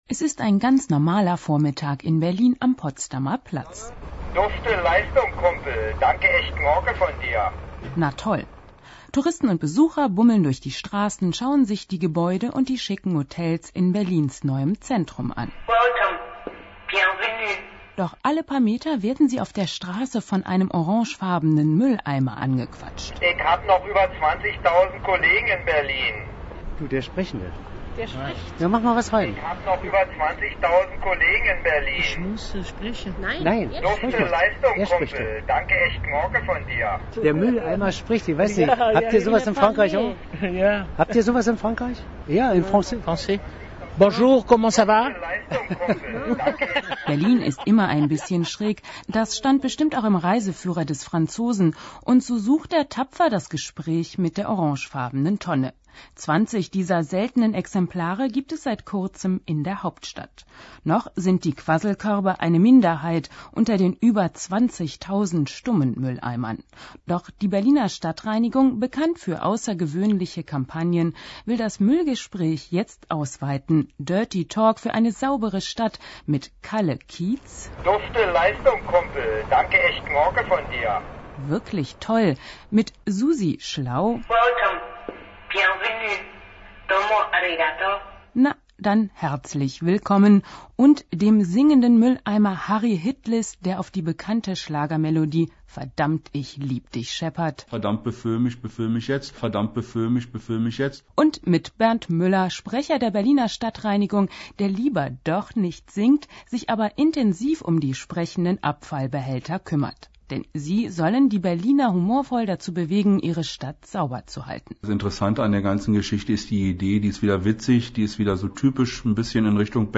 Die d-radio Reportage